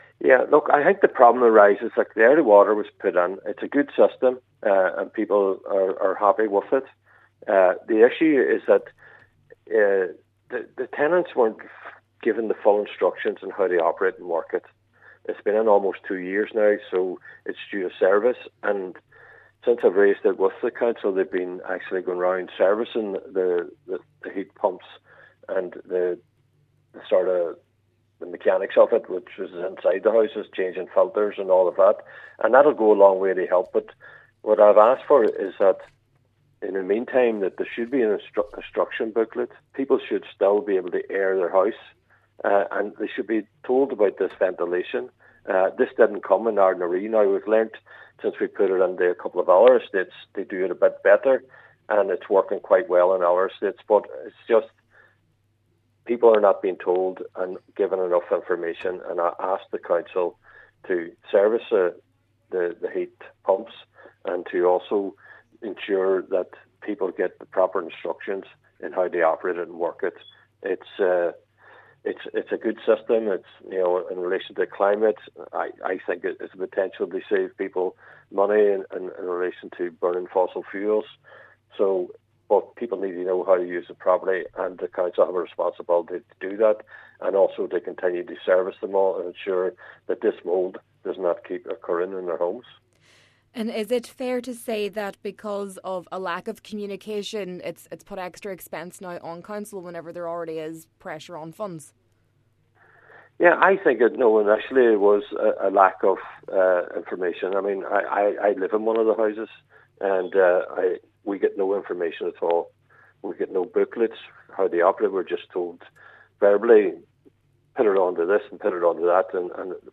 Mayor of the MD, Cllr Gerry McMonagle says not enough information was available provided to the house occupants at the time: